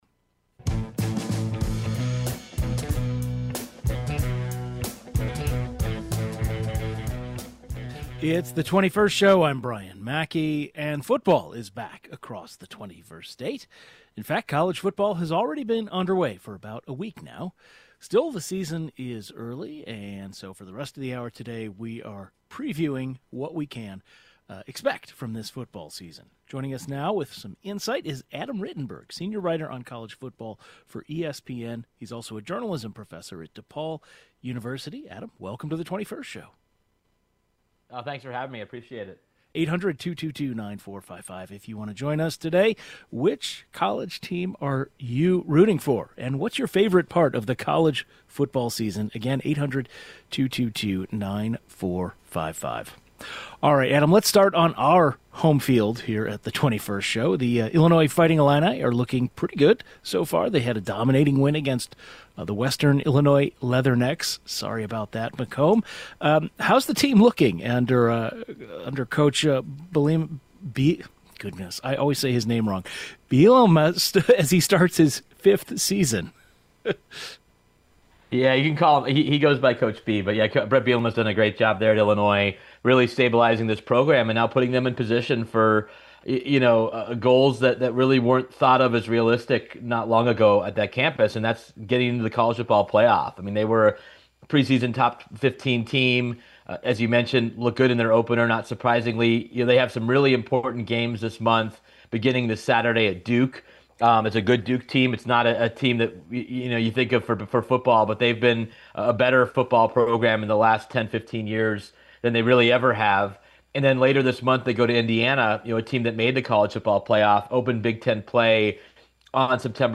College football is back across the 21st state and we are offering a preview of what fans can expect this season. The 21st Show is Illinois' statewide weekday public radio talk show, connecting Illinois and bringing you the news, culture, and stories that matter to the 21st state.
A journalist who covers college football joins the program to offer his predictions.